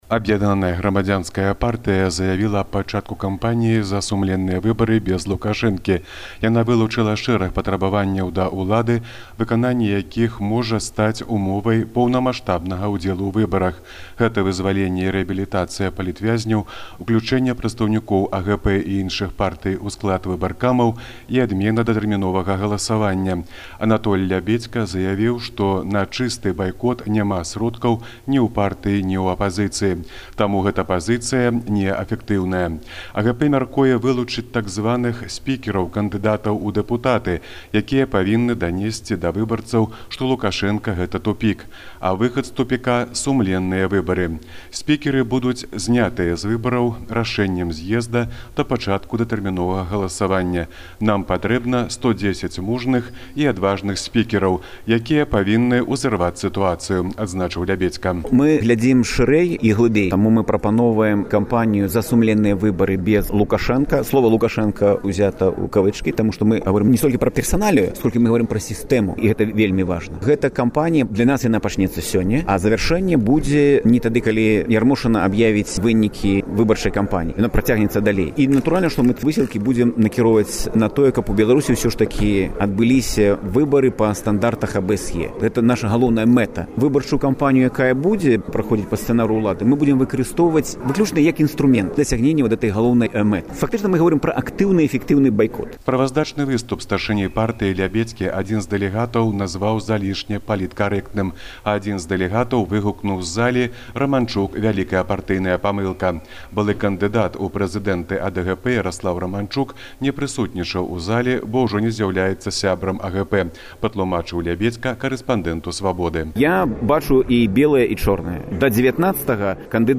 Зьезд АГП. Жывы рэпартаж